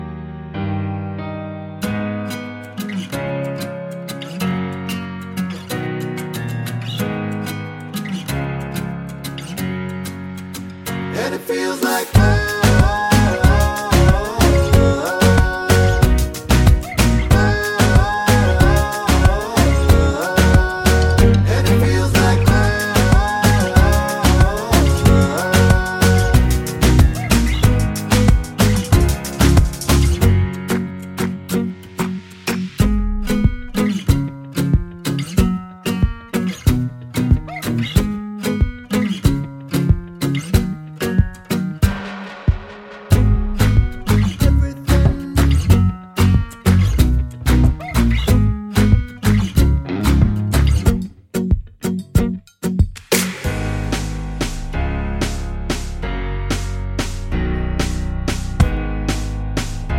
With Explicit Backing Vocals Pop (2010s) 3:29 Buy £1.50